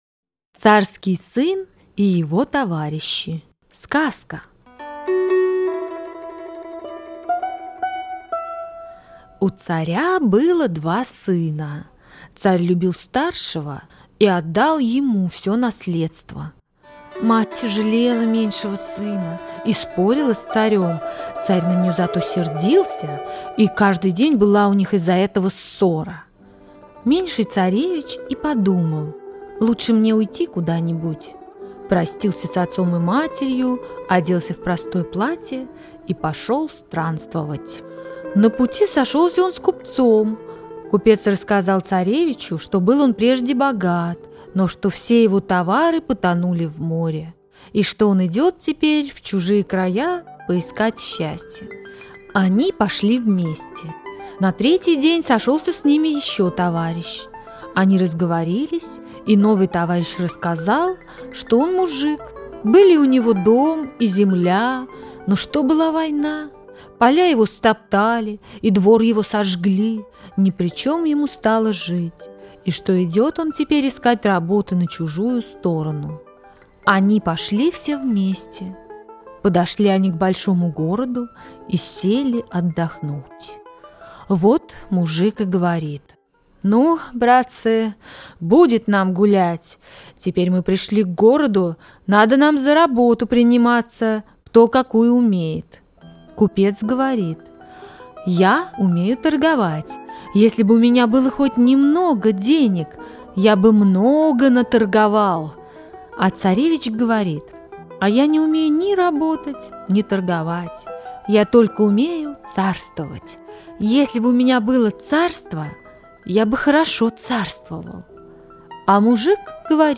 Царский сын и его товарищи - аудиосказка Льва Толстого - слушать онлайн | Мишкины книжки
Царский сын и его товарищи – Толстой Л.Н. (аудиоверсия)